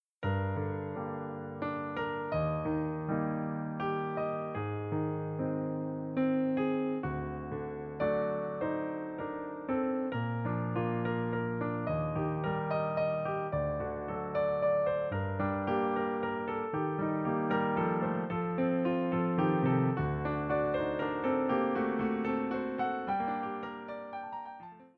Seven evocative piano solos, minimalist in style.
A waltz with some rubato.